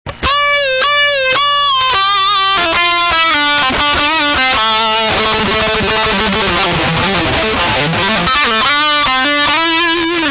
lead guitar.